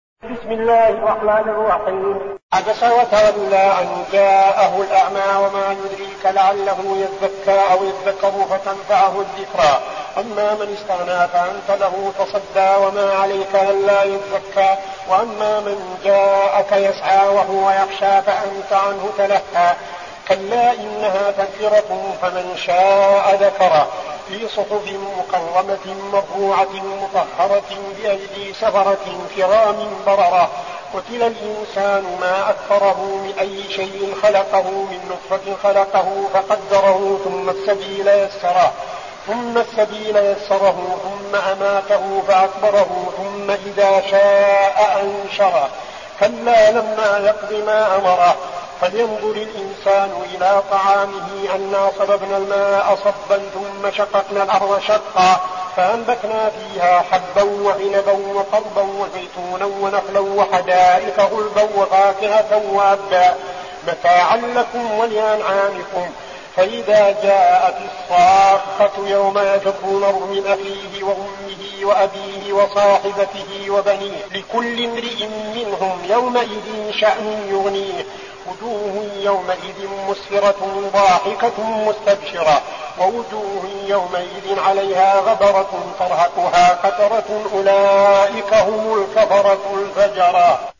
المكان: المسجد النبوي الشيخ: فضيلة الشيخ عبدالعزيز بن صالح فضيلة الشيخ عبدالعزيز بن صالح عبس